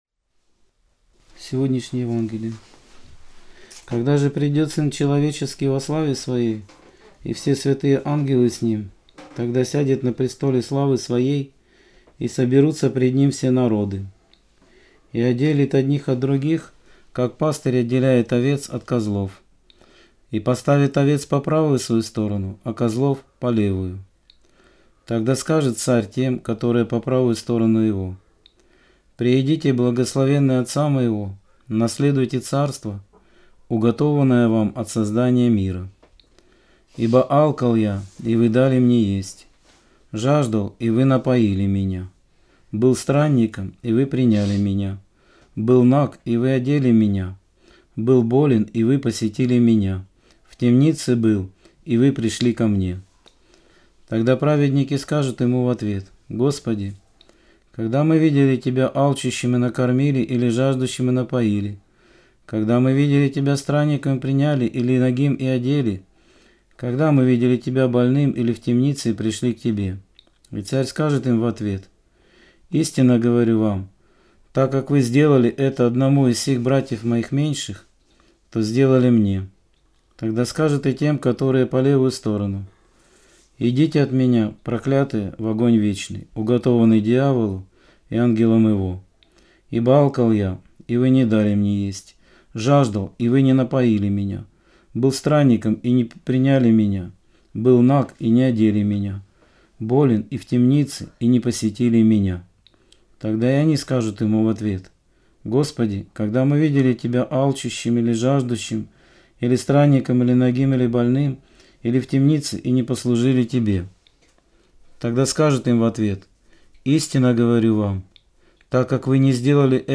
Аудио-проповедь 3.03.2019